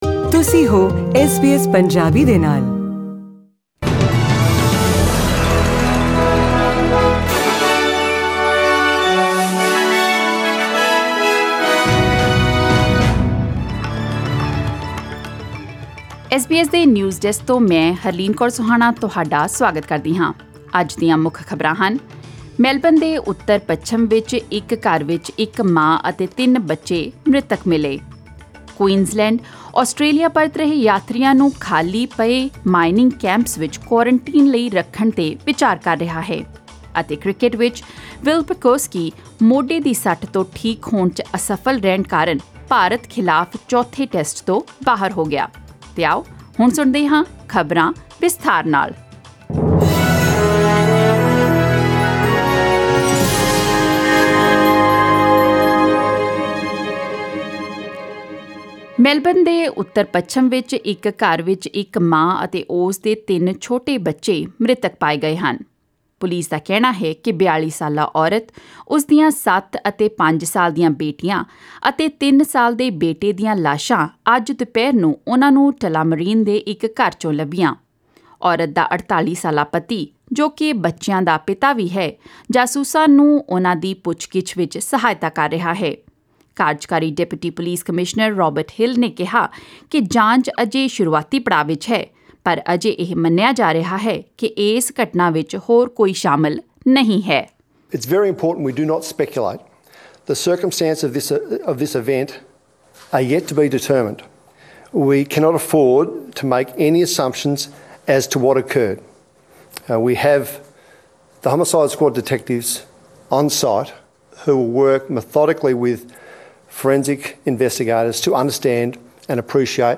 Australian News in Punjabi: 14 January 2021